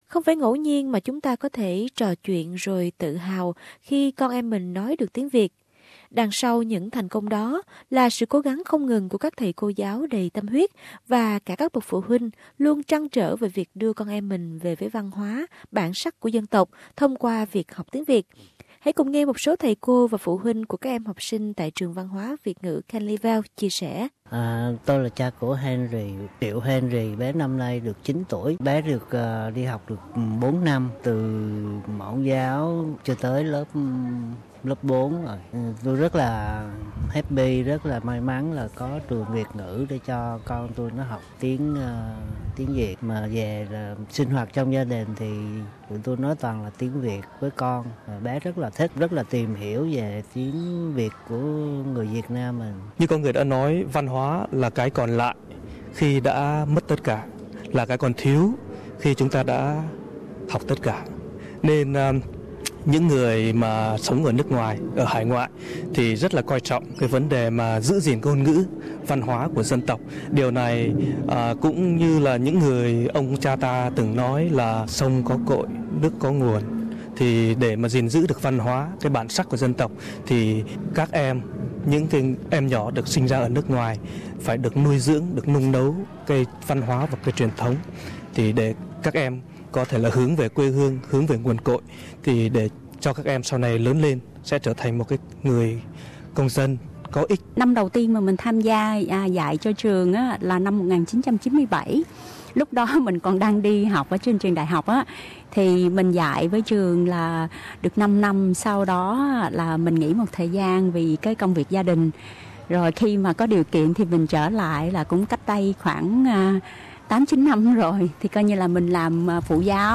Ngôn ngữ là một phần của văn hóa. SBS trò chuyện với quý thầy cô và các bậc phụ huynh của Trường Văn hóa Việt ngữ Canley Vale, được cho biết, học tiếng Việt các em đang được học về văn hóa của chính quê hương mình.